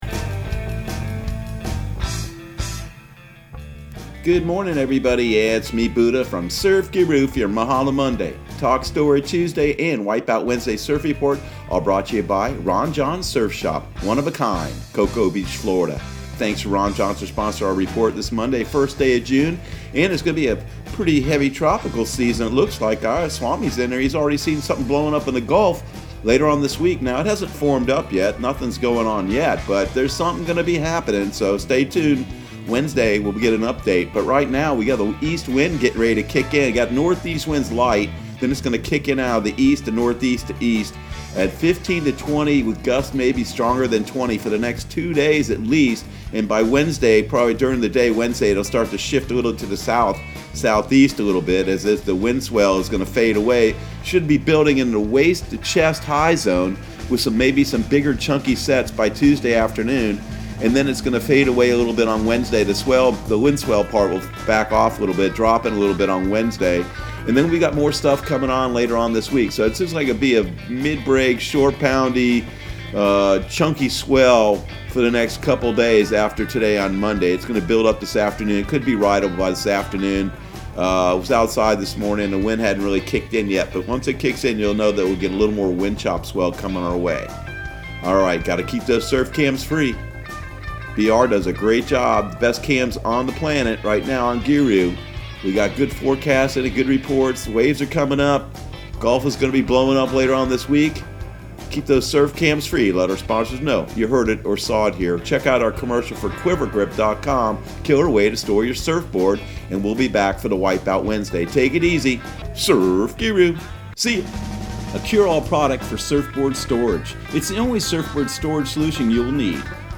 Surf Guru Surf Report and Forecast 06/01/2020 Audio surf report and surf forecast on June 01 for Central Florida and the Southeast.